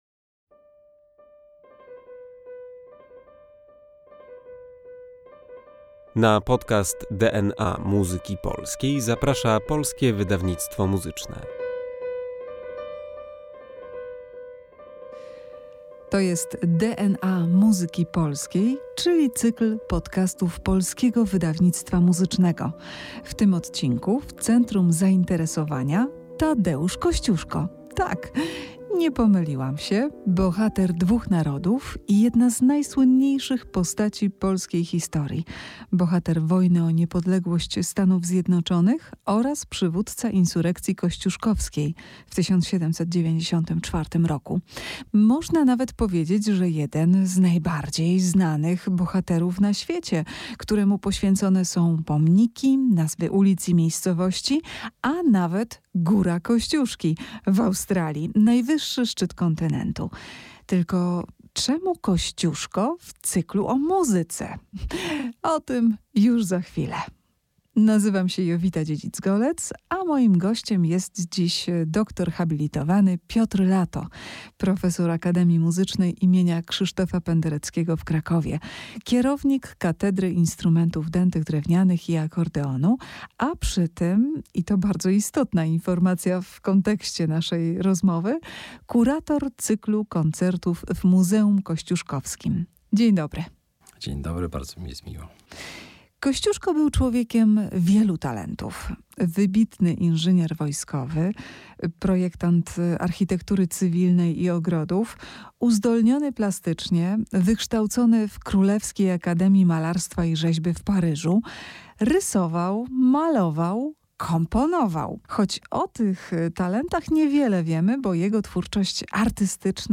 Odpowiedź poznacie, słuchając dzisiejszej rozmowy.